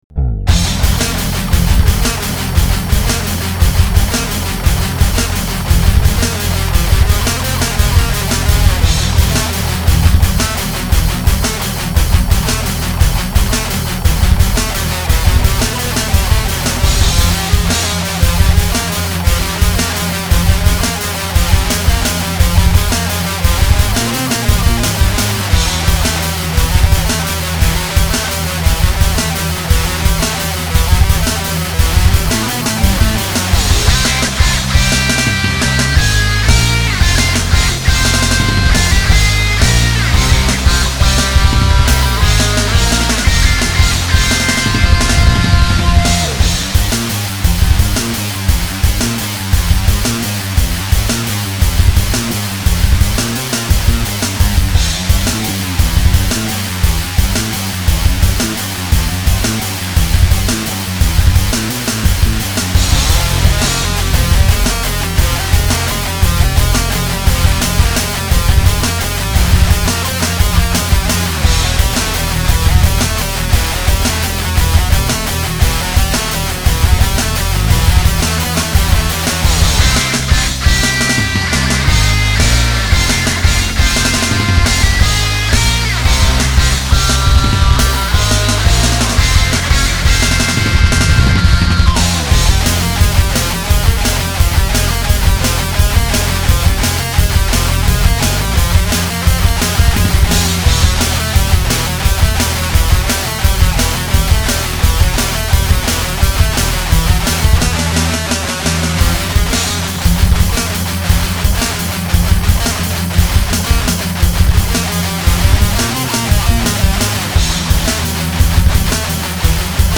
arrange track